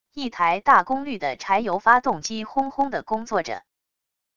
一抬大功率的柴油发动机轰轰的工作着wav音频